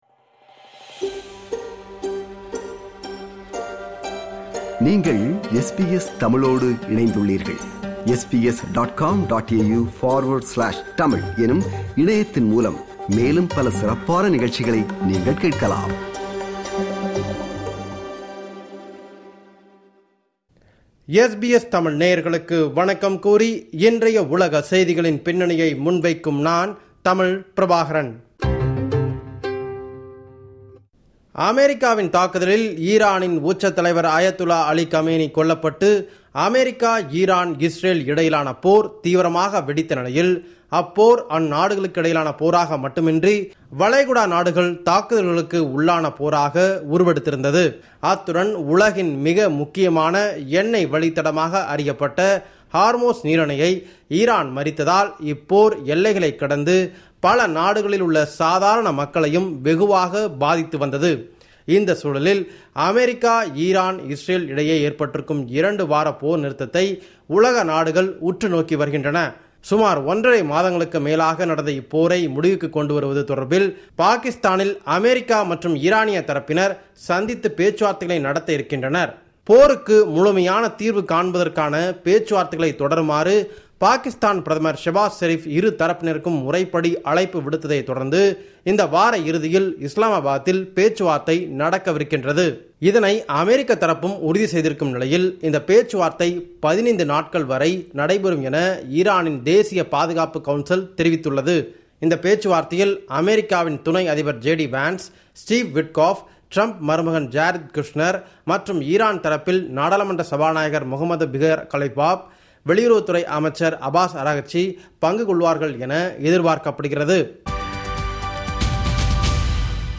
உலகம்: இந்த வார செய்திகளின் தொகுப்பு